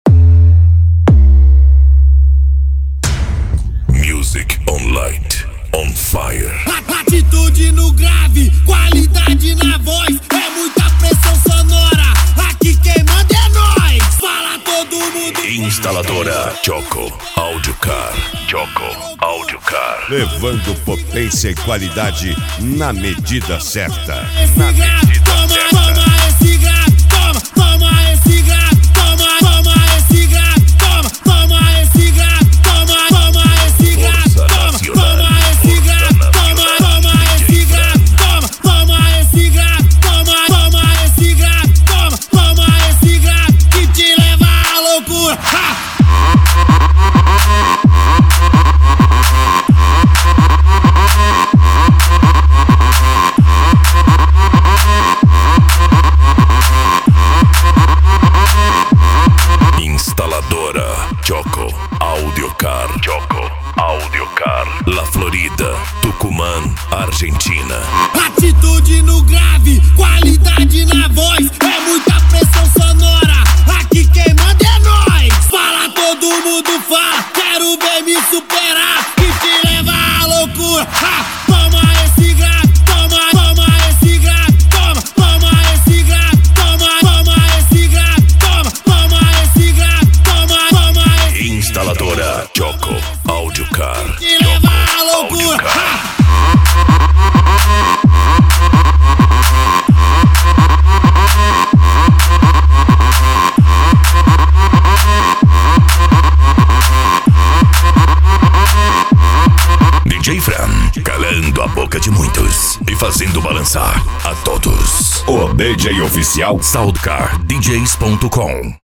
Bass
Racha De Som
Remix